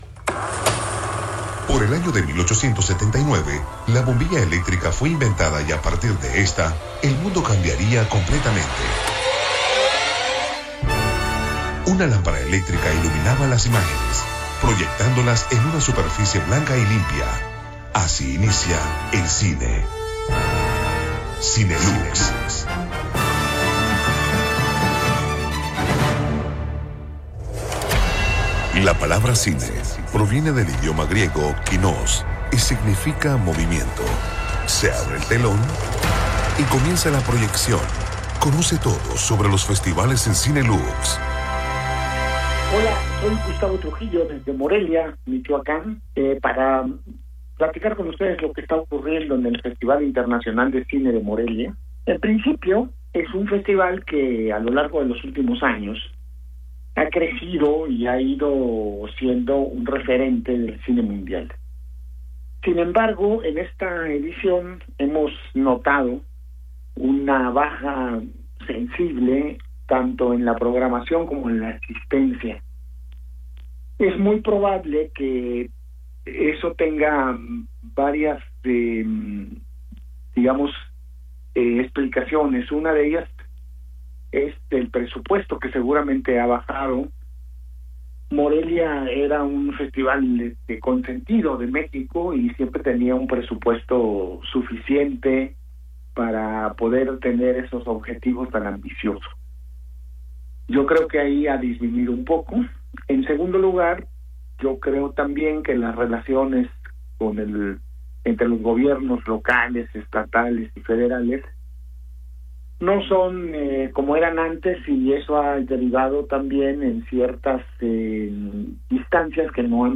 Cinelux, desde el Festival Internacional de Cine de Morelia 2019